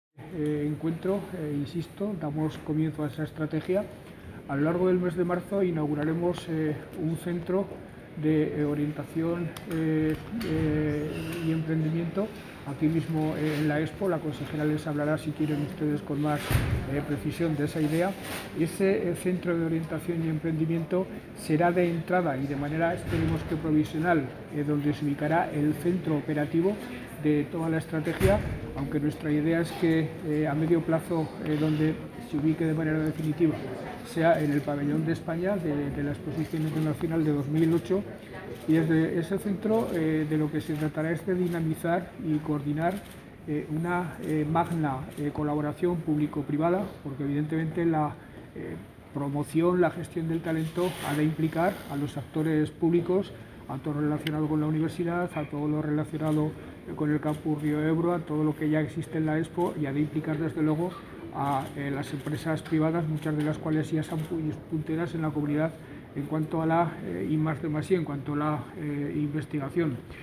Lambán sobre la sede física del Centro Aragonés del Talento y su objetivo